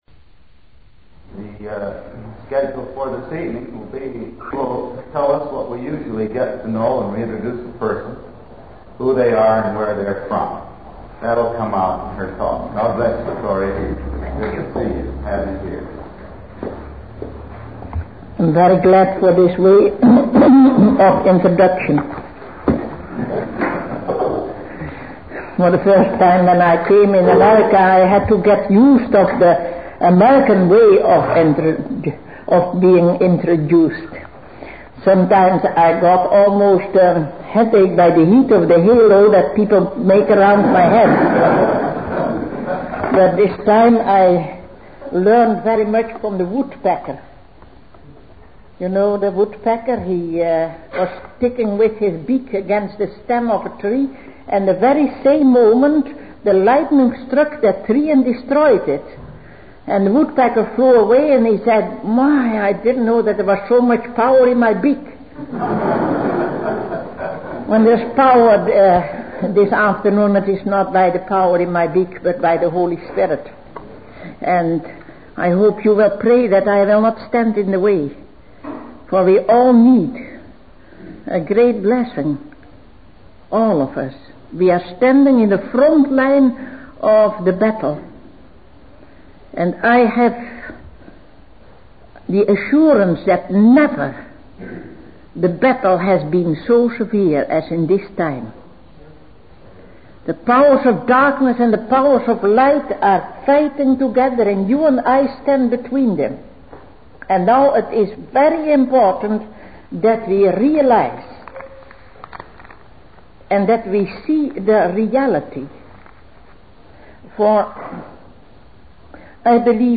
In this sermon, the speaker shares his experience of being introduced in Alaska and compares it to the power of the Holy Spirit. He emphasizes the importance of not standing in the way of the Holy Spirit and the need for a great blessing.